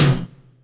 Tuned drums (C# key) Free sound effects and audio clips
• Acoustic Tom Sound C# Key 26.wav
Royality free tom drum sound tuned to the C# note. Loudest frequency: 1338Hz
acoustic-tom-sound-c-sharp-key-26-Da7.wav